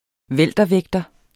Udtale [ ˈvεlˀdʌˌvεgdʌ ]